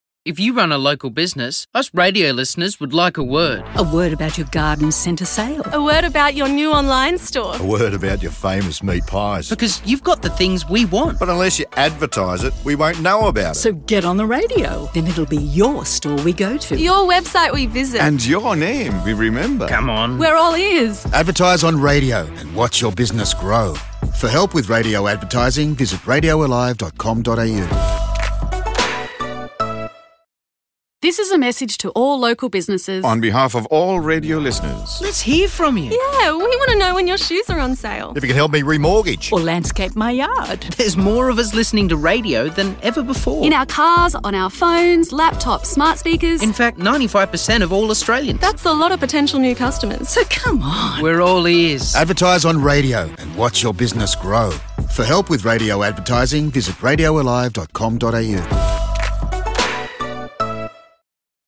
Développées par CRA, en consultation avec Eardrum, les deux publicités radio présentent un échantillon d’auditeurs encourageant les entreprises à communiquer les produits et services qu’elles proposent, alors que les consommateurs recherchent des idées et des inspirations d’achat à la radio.